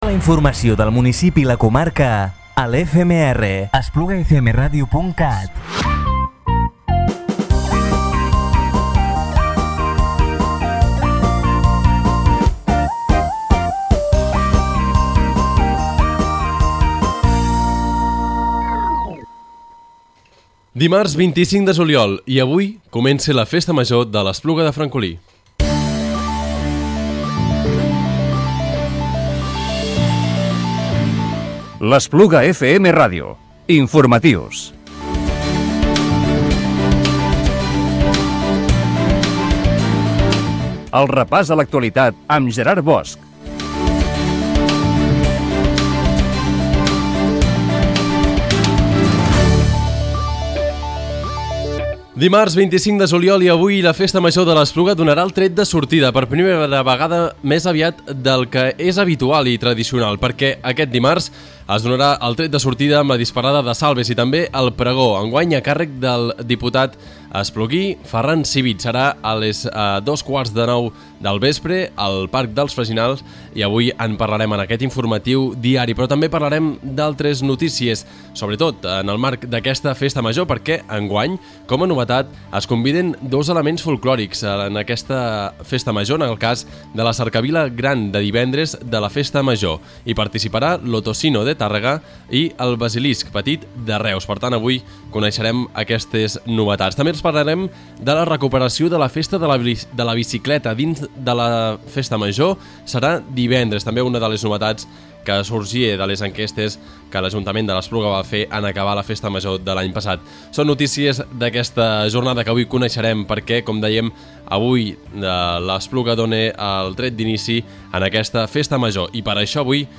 Informatiu diari del dimarts 25 de juliol del 2017